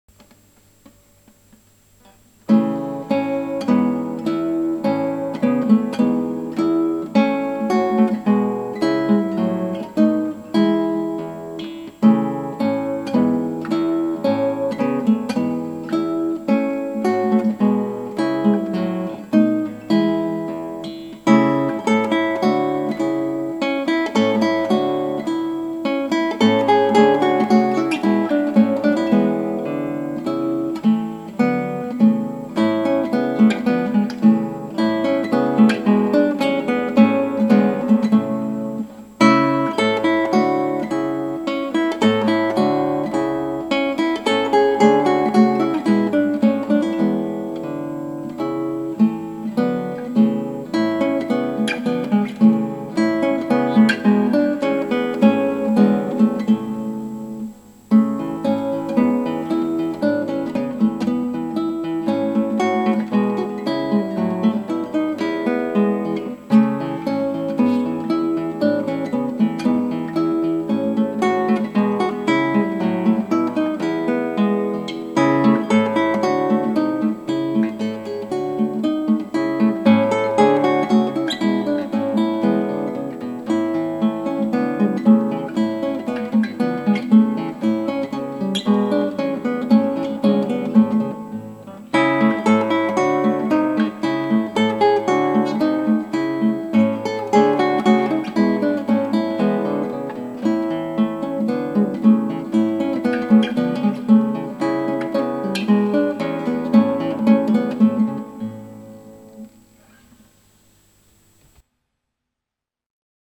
クラシックギター　ストリーミング　コンサート
上記構成にノイズレスシールド・・・これ最強。